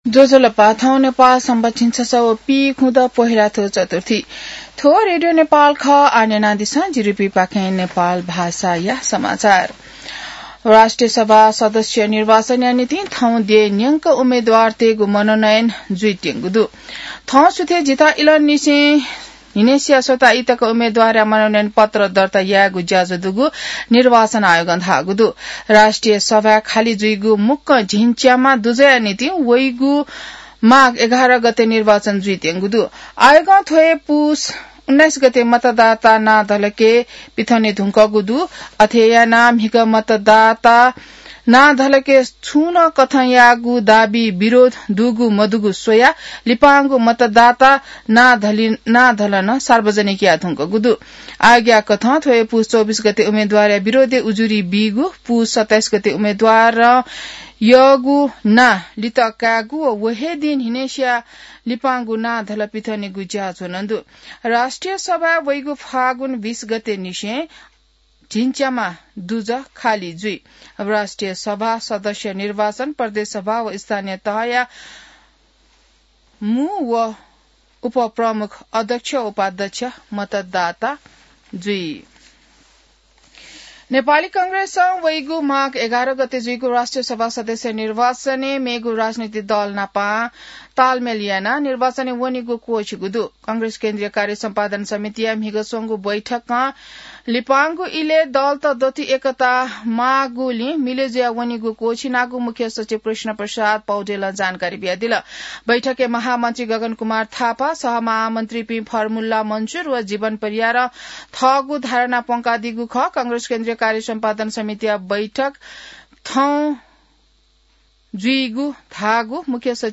An online outlet of Nepal's national radio broadcaster
नेपाल भाषामा समाचार : २३ पुष , २०८२